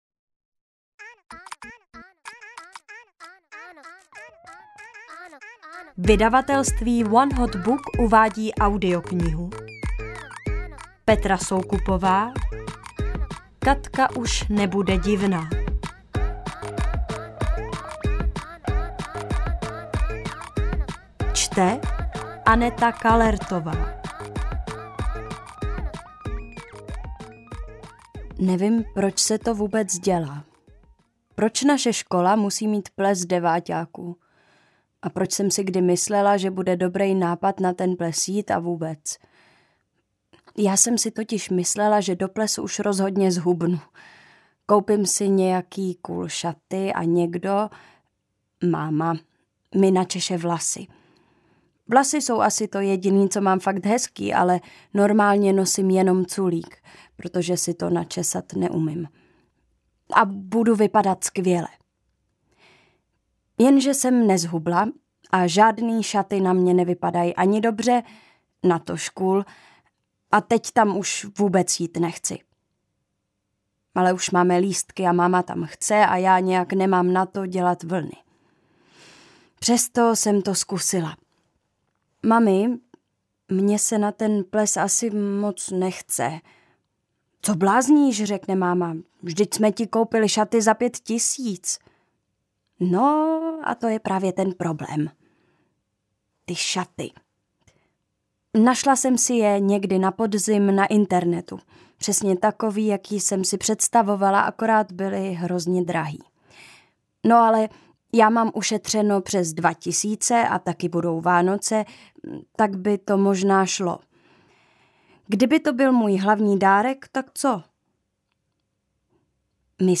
Katka už nebude divná audiokniha
Ukázka z knihy